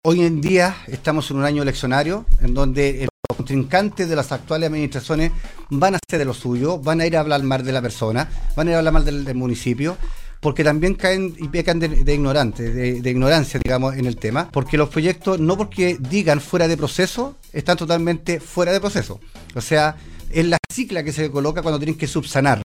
Por su parte el consejero regional Gerardo Contreras, calificó como argumentos políticos estas denuncias de supuesto plagio, durante entrevista en Mosaico